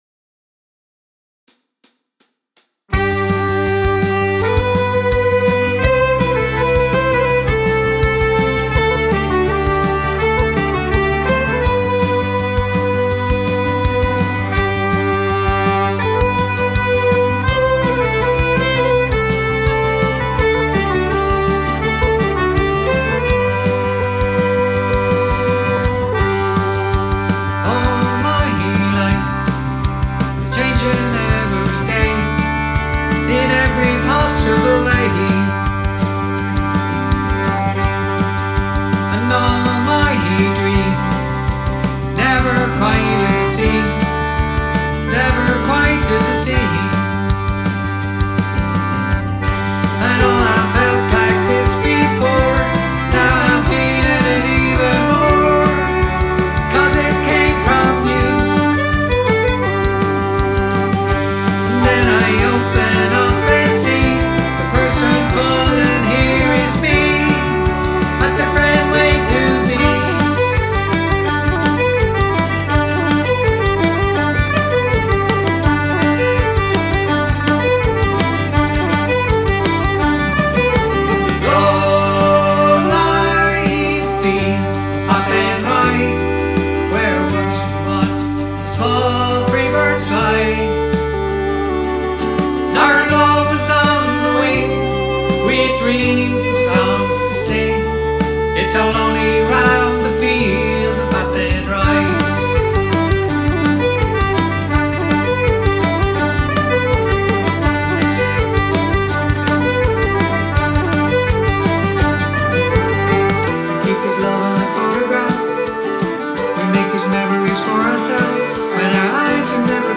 Main Wedding band
Wedding Band sample mixes